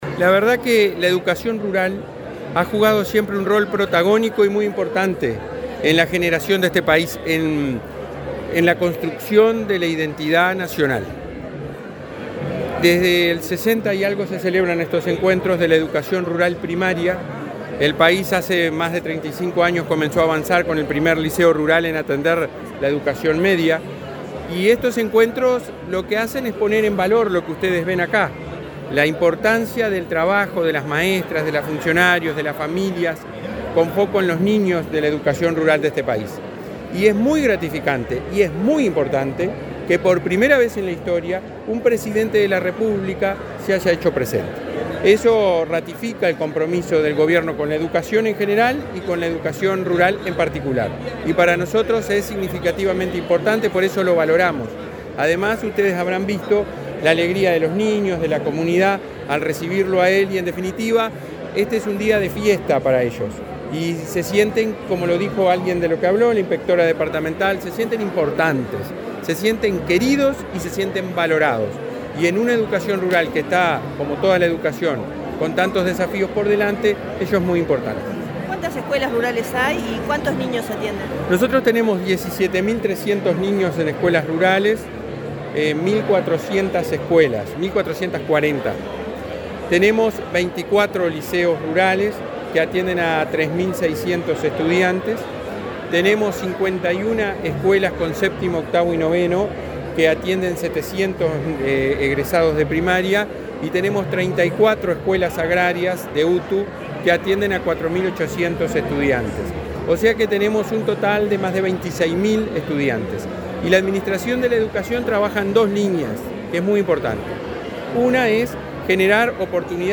Entrevista al presidente de la ANEP, Robert Silva